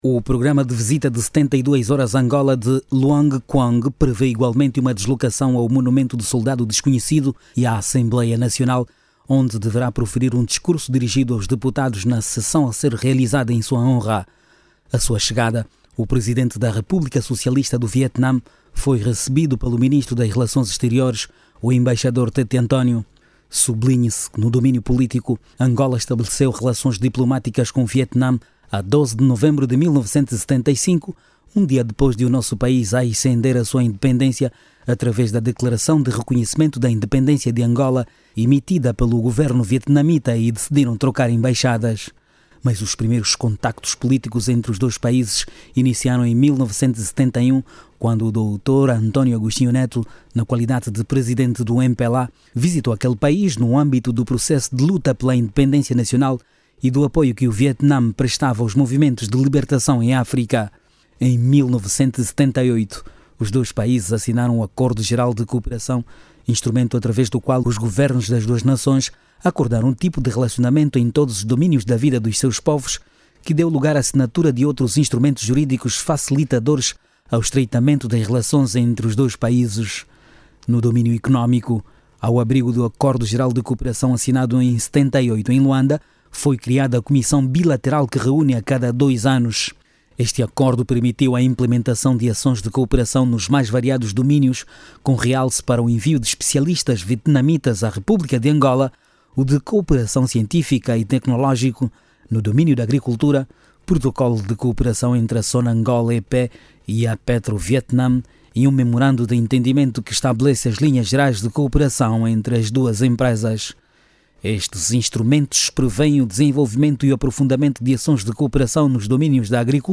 NOTÍCIAS